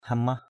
/ha-mah/ (đg.) tống trừ = chasser, éloigner. hamah brah hMH bH tống gạo (dùng gạo cúng để tống trừ tà) = jeter du riz (pour se protéger d’un maléfice).
hamah.mp3